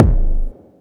kickldk35.wav